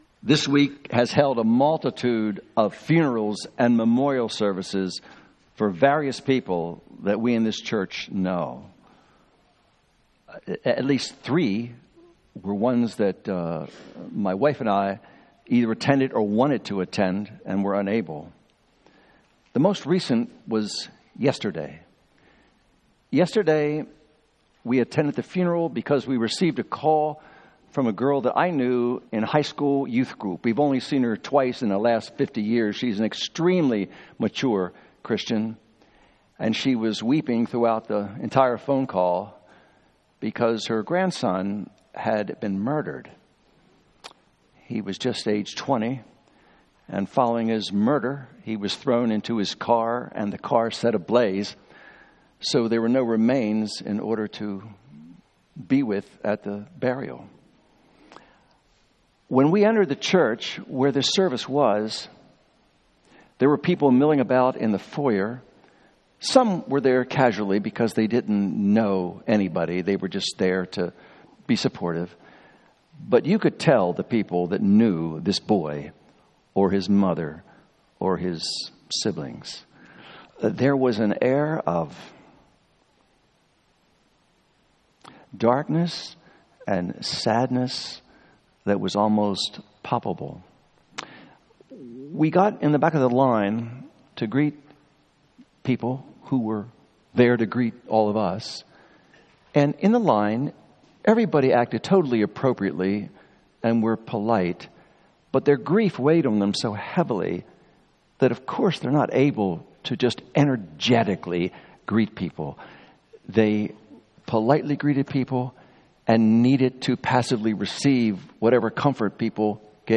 Sermon Outline 1.